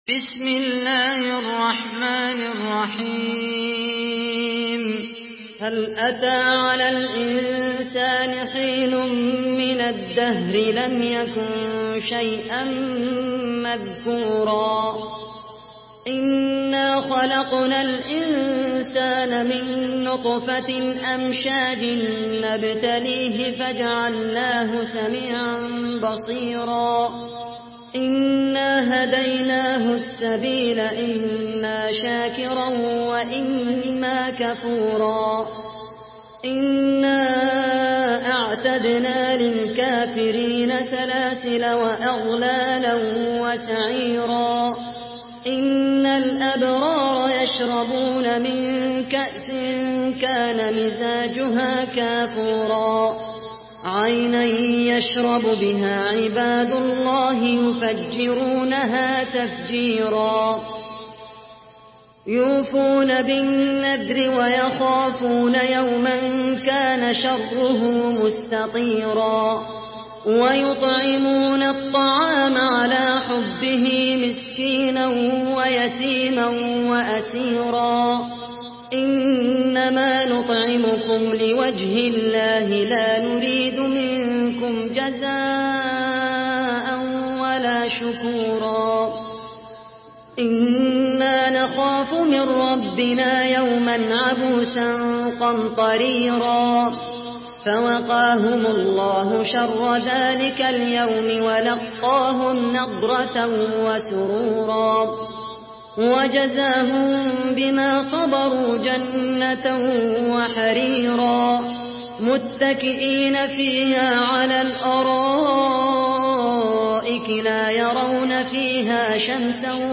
سورة الإنسان MP3 بصوت خالد القحطاني برواية حفص عن عاصم، استمع وحمّل التلاوة كاملة بصيغة MP3 عبر روابط مباشرة وسريعة على الجوال، مع إمكانية التحميل بجودات متعددة.
مرتل حفص عن عاصم